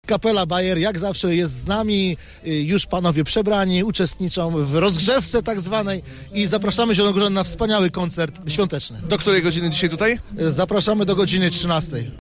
Jarmark Bożonarodzeniowy w ZRRT
kusnierz-jarmark-gielda-2.mp3